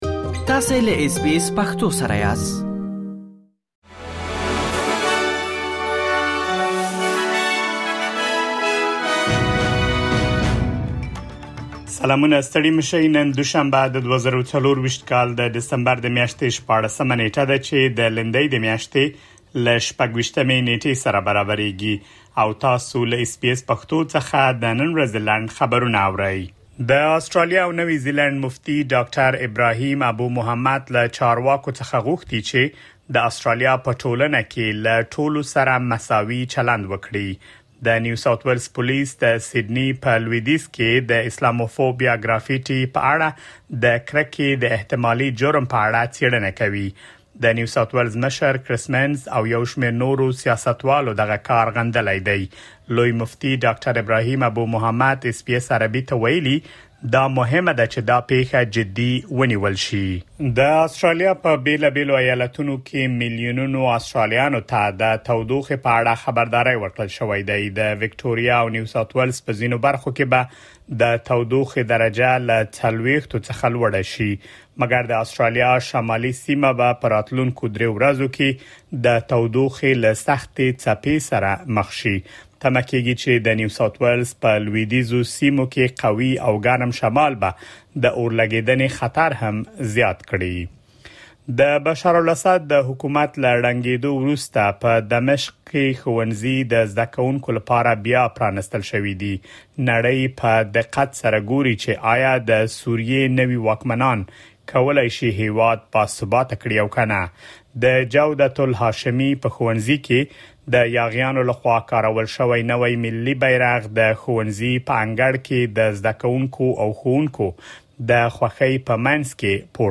د اس بي اس پښتو د نن ورځې لنډ خبرونه |۱۶ ډسمبر ۲۰۲۴
د اس بي اس پښتو د نن ورځې لنډ خبرونه دلته واورئ.